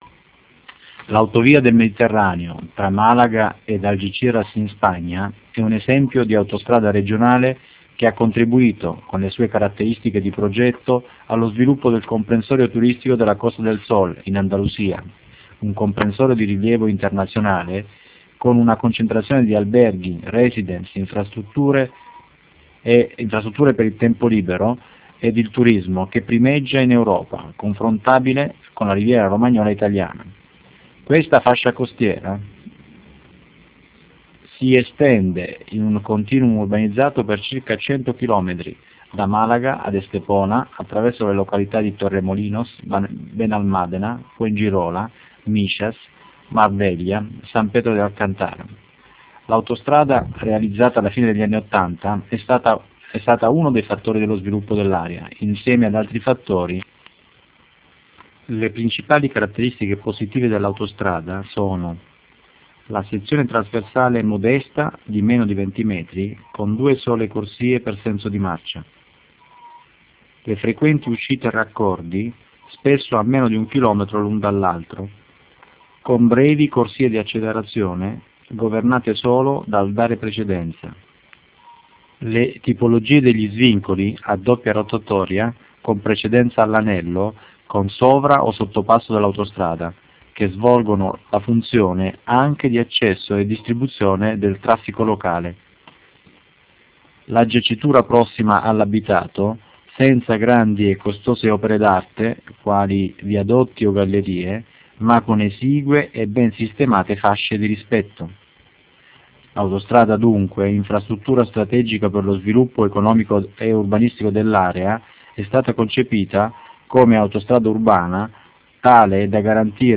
COMMENTO AUDIO
narrazione delle principali caratteristiche del caso studio